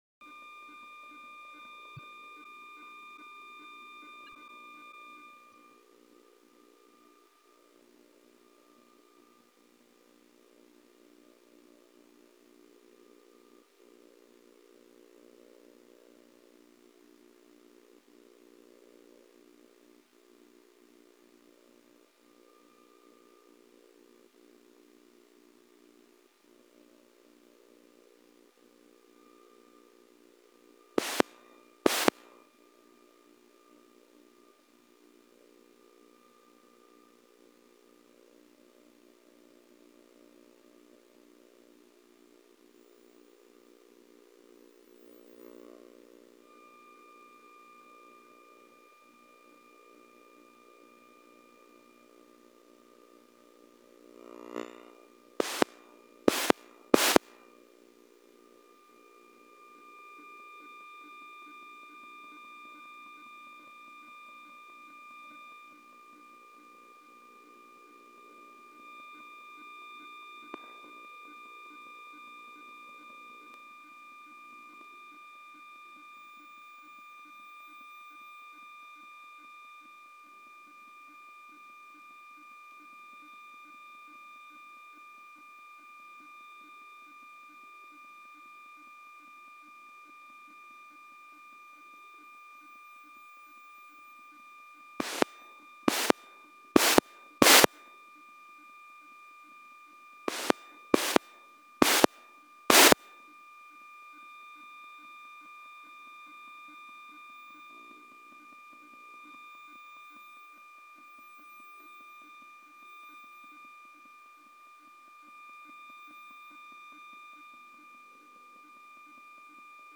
CDMA_uplink_reverse_link_access_probes.mp3